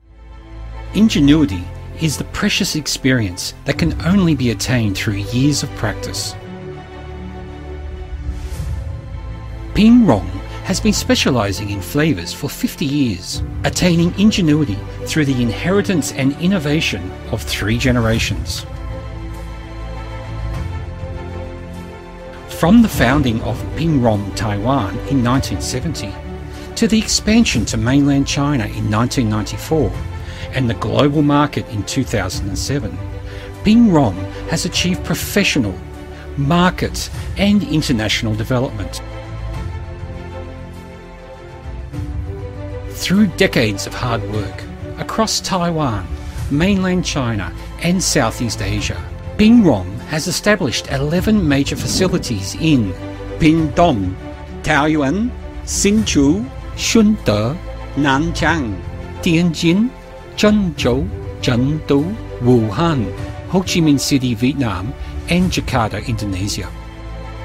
Giọng nam người Úc